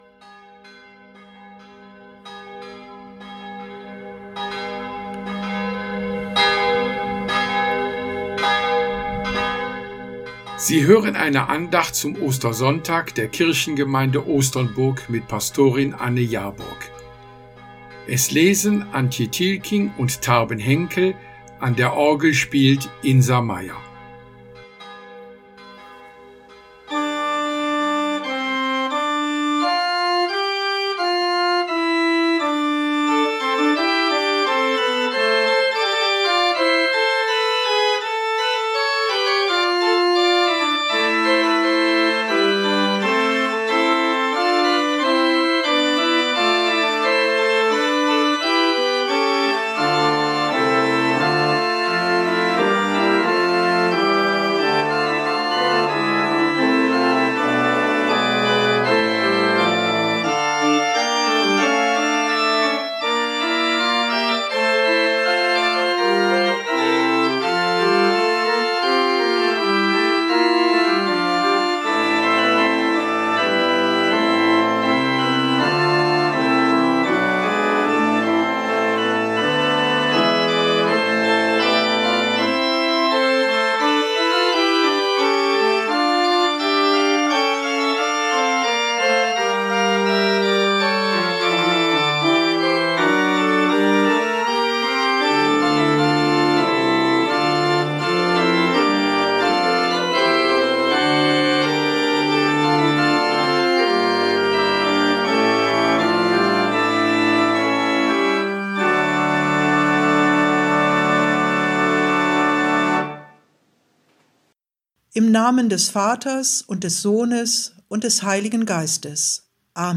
Diese Andacht wurde am Ostersonntag, 12.4.2020 um 10:30 Uhrim Hörfunk von Oldenburg Eins gesendet.
Lied EG 99: Christ ist erstanden